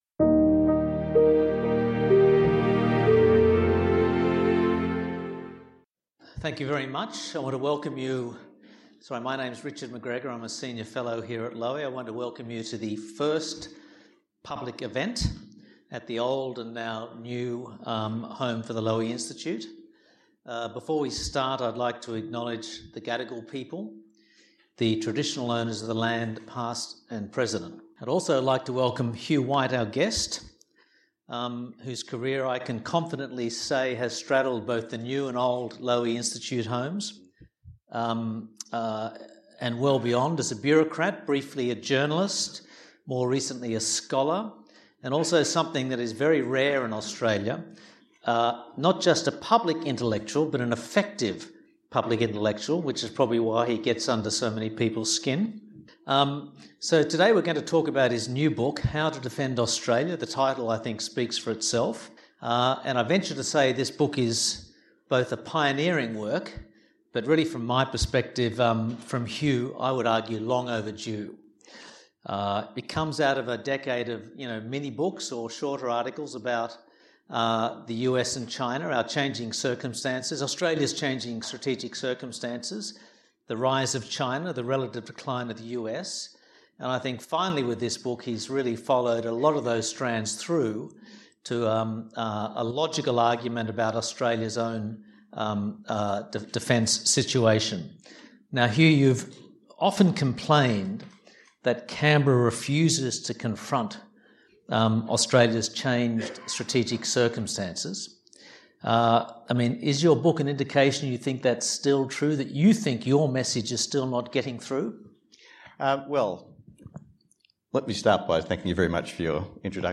In Conversation: Hugh White on How to Defend Australia